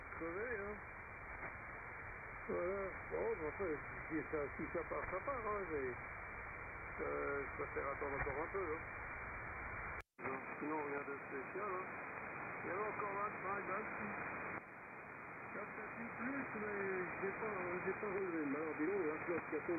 Second 00 - 10> Winradio G33DDC
Some recordings are of very weak, barely perceptible channels.
↓ Hamradio 80m Band.